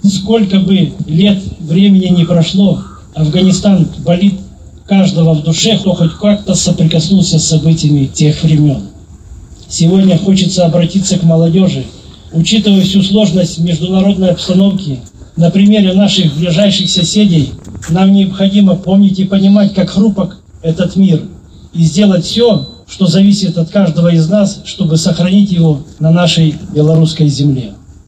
В Барановичах у памятника воинам-интернационалистам собрались ветераны-афганцы, члены их семей, матери и вдовы погибших, руководство города, представители общественных организаций, предприятий, силовых структур, военнослужащие, духовенство, молодежь, жители города.